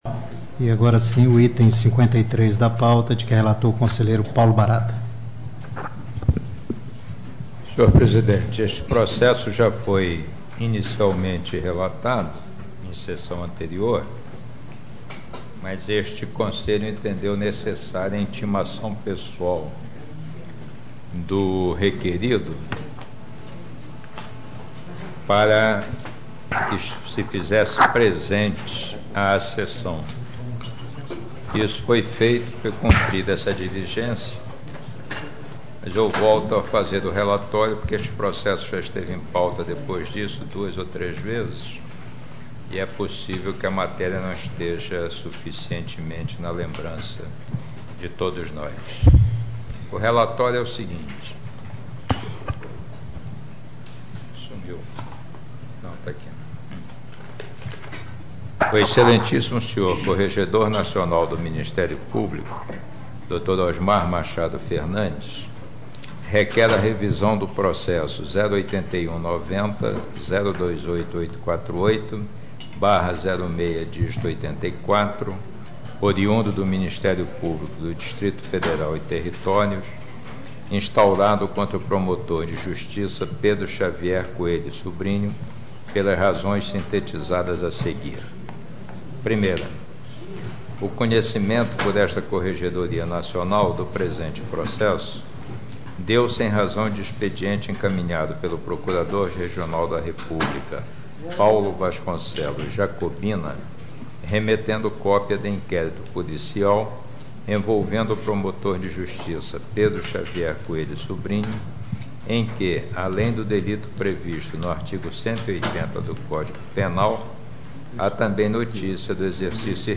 Acompanhe aqui o áudio da sessão, referente a esse processo, com relatório e voto do conselheiro Paulo Barata.